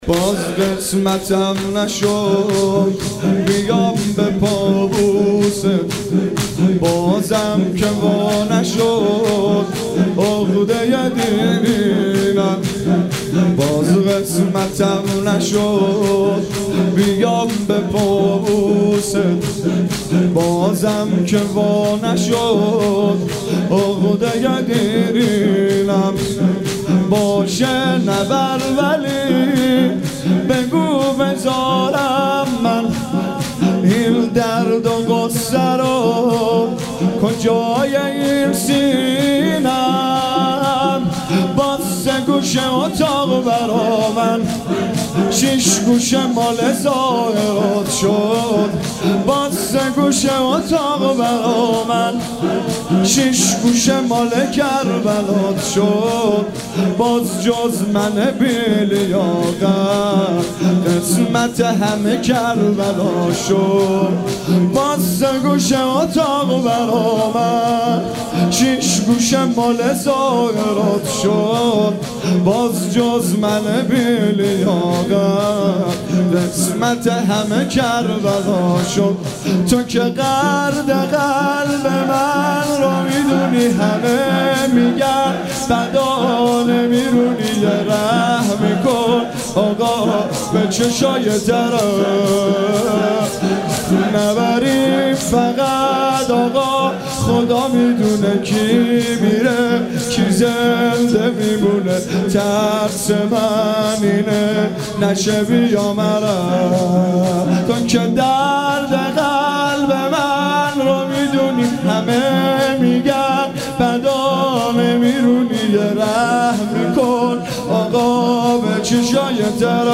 باز قسمتم نشد/شور